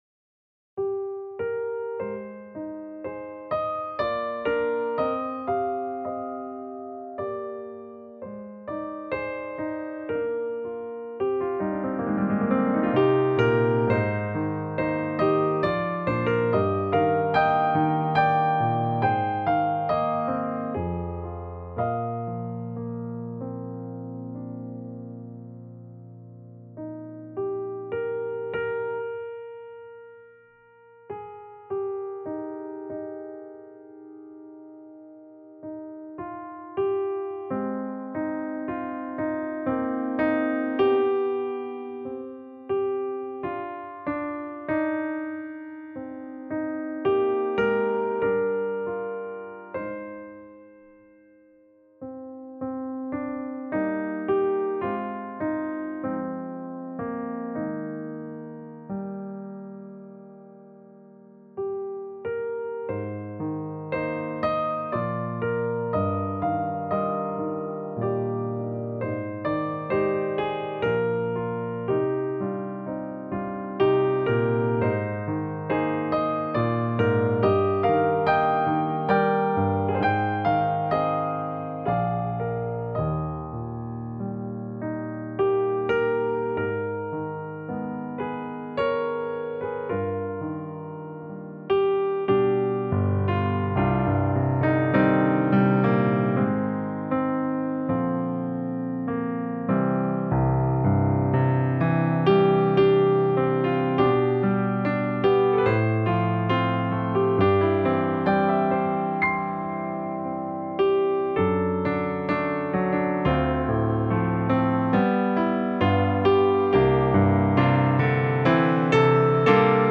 Voicing/Instrumentation: Piano Solo